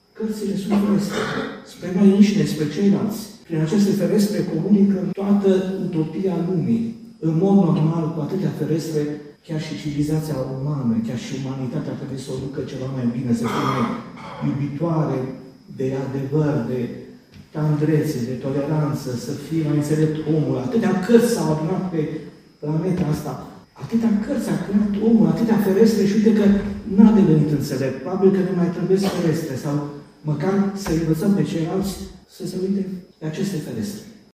Aseară, la Botoșani, au fost decernate Premiile Culturii Naționale, în cadrul unei Gale care a încheiat prima zi din seria Zilelor Eminescu.
Ceremonia s-a desfășurat în sala Teatrului „Mihai Eminescu” din Botoșani și a fost organizată de Memorialul Ipotești – Centrul Național de Studii „Mihai Eminescu”, cu sprijinul Ministerului Afacerilor Externe.